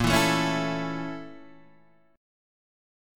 A#mM7b5 chord {6 7 7 6 5 5} chord